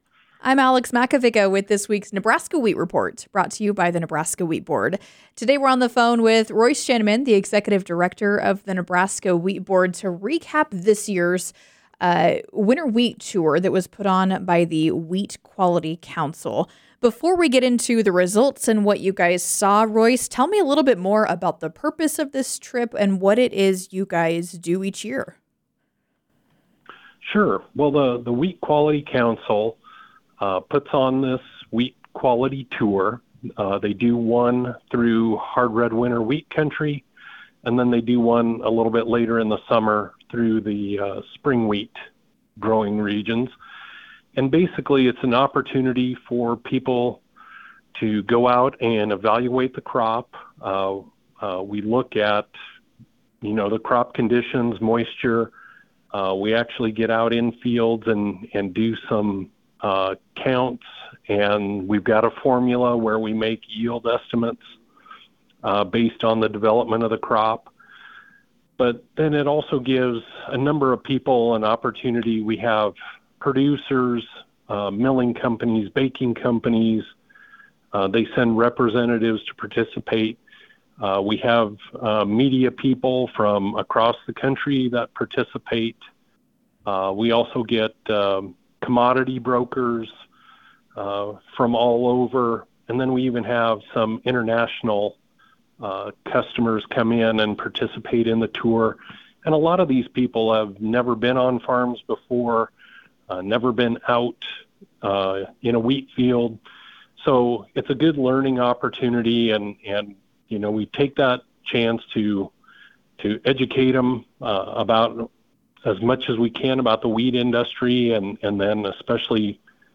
The Wheat Report are interviews conducted with farmers and wheat industry representatives regarding current events and issues pertaining to the Nebraska Wheat Board.